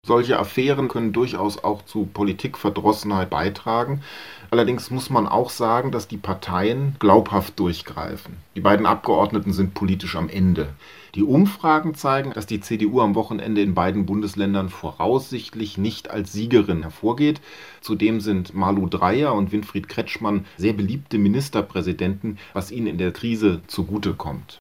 Politikwissenschaftler